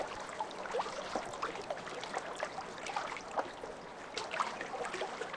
lake_waves_2_calm.ogg